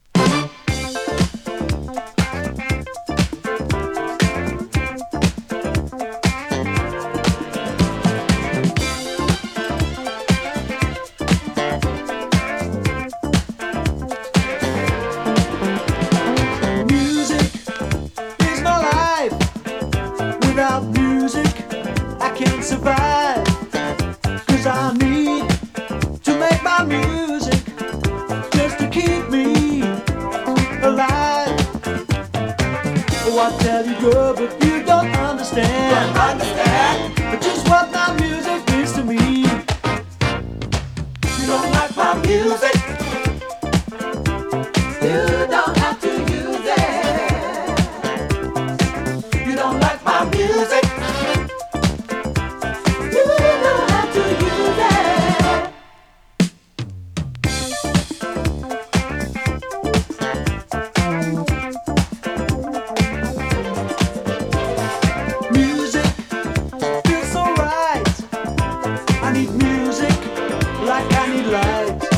ユーロファンク
同系の哀愁ブギー